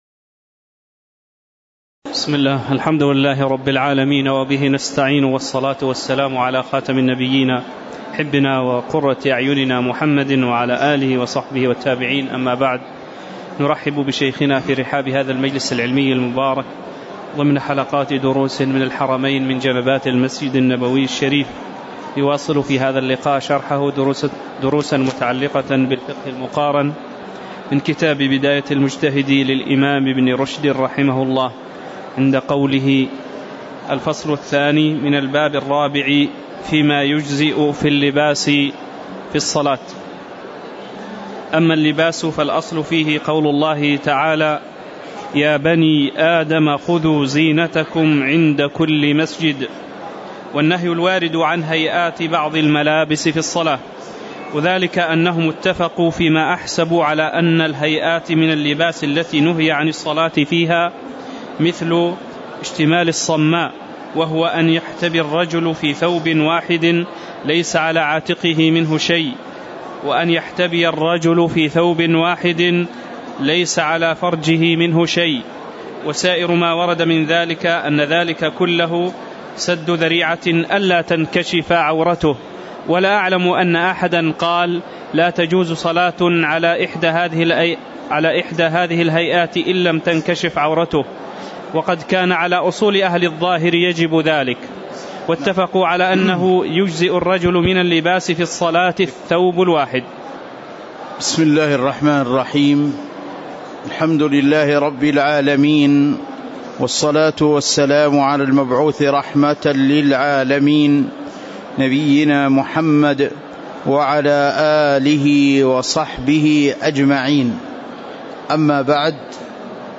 تاريخ النشر ١٤ ربيع الأول ١٤٤١ هـ المكان: المسجد النبوي الشيخ